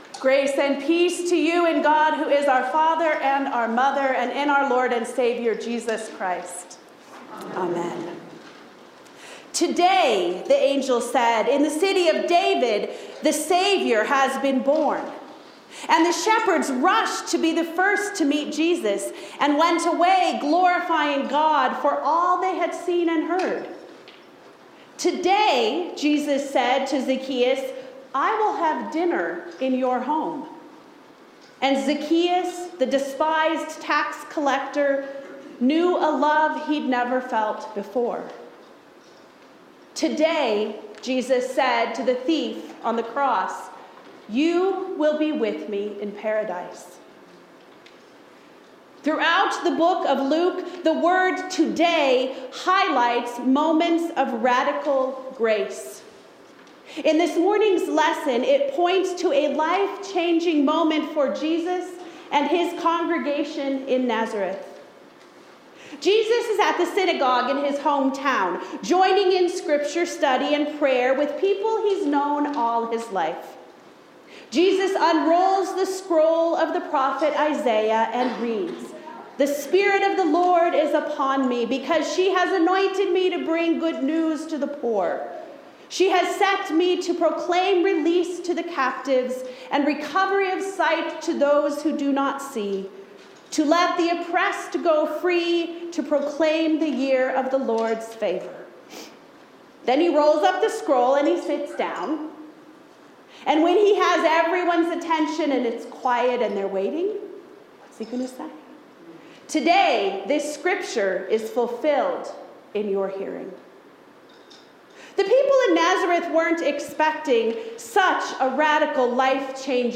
Sermons – Page 4 – All Saints Lutheran Church, ELCA